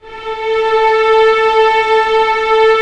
Index of /90_sSampleCDs/Roland LCDP13 String Sections/STR_Violins IV/STR_Vls7 _ Orch